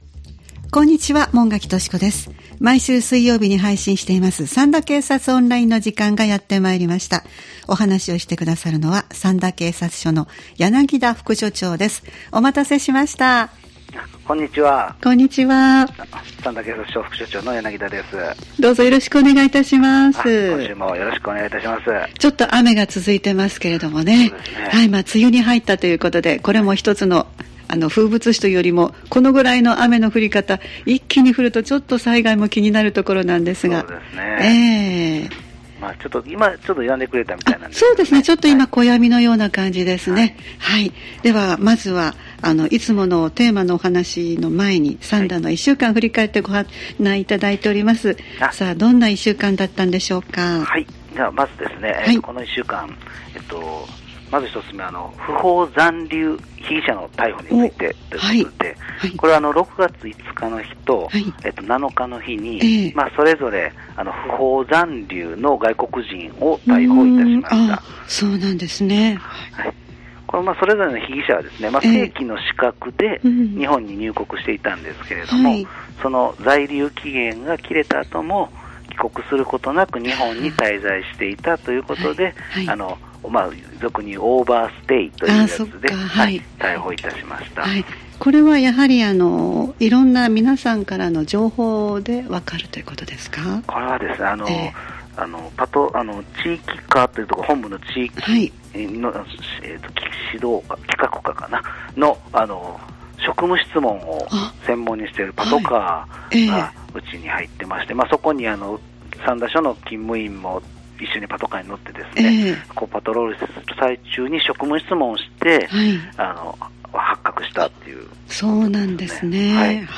三田警察署に電話を繋ぎ、一週間の事件事故、防犯情報、警察からのお知らせなどをお聞きしています（再生ボタン▶を押すと番組が始まります）